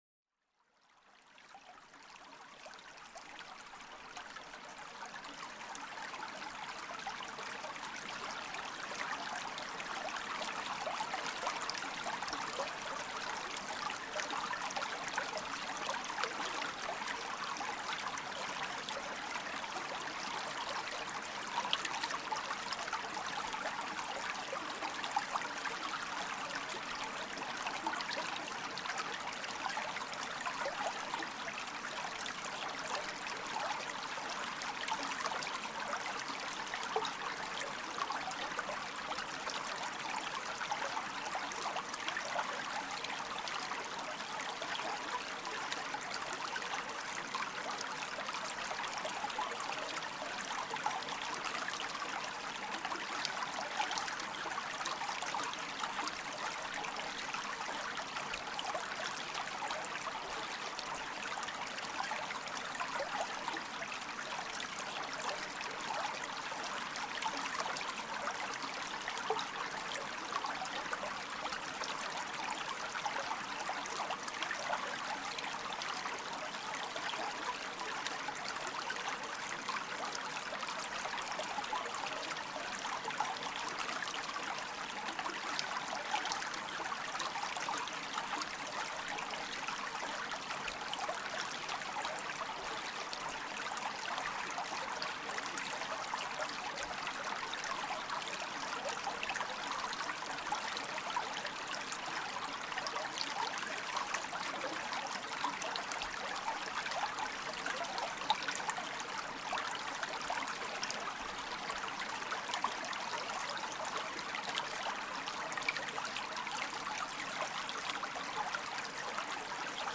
Découvrez le son captivant d'un ruisseau tranquille, où l'eau danse doucement sur les pierres polies par le temps. Laissez-vous emporter par le murmure apaisant qui éveille les sens et enrichit l'âme.